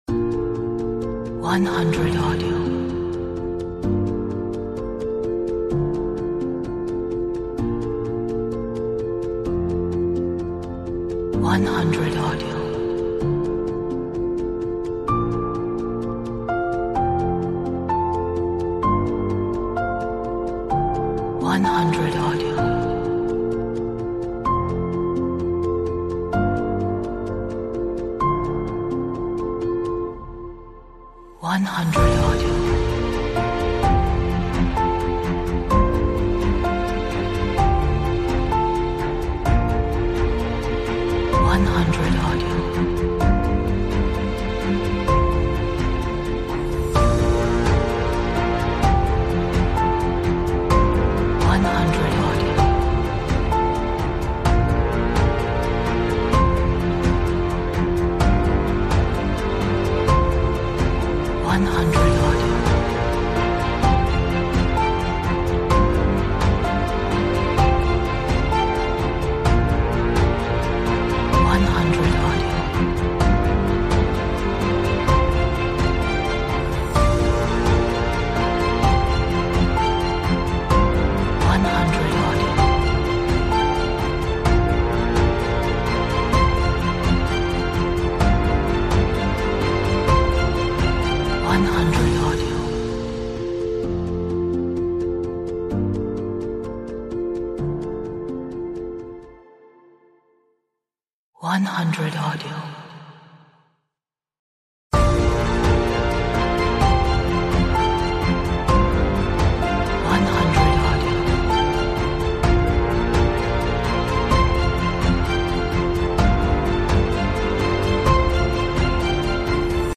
Atmospheric, dramatic, epic, energetic, romantic,